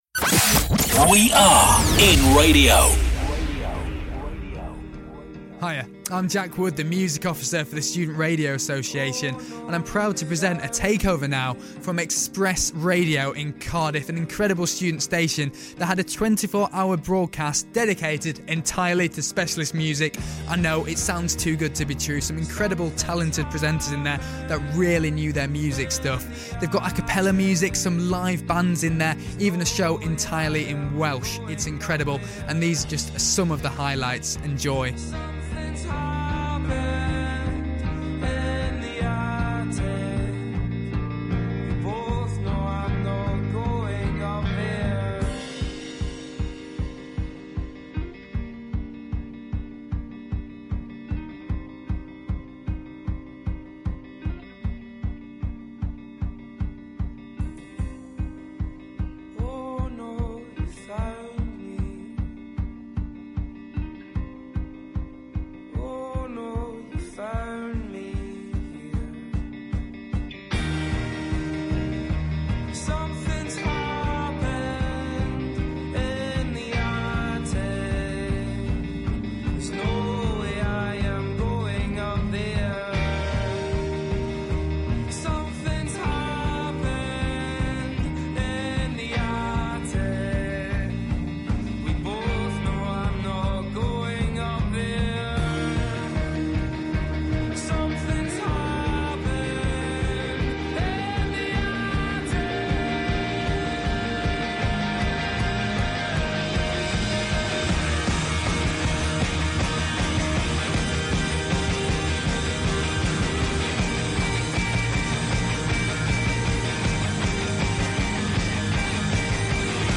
From A Capella to grunge and grime, every genre of music was covered in Xpress Radio's 24 hour Specialist Music Takeover.